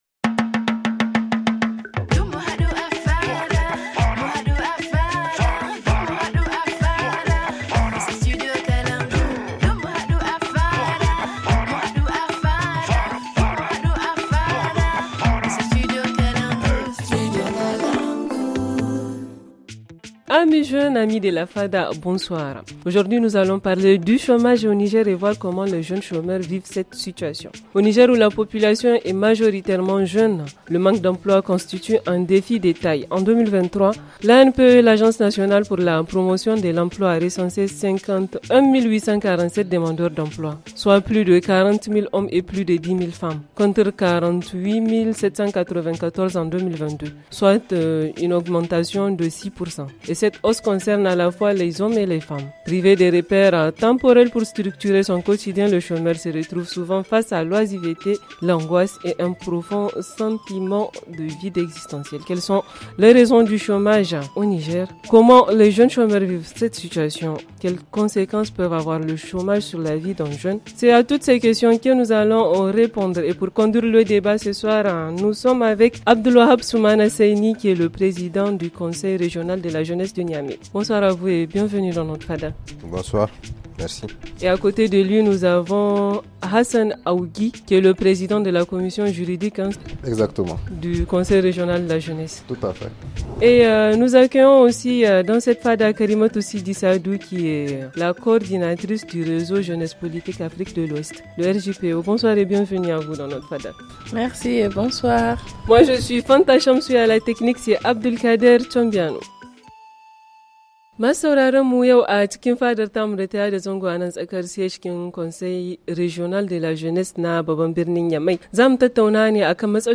FR-HA Franco-Haoussa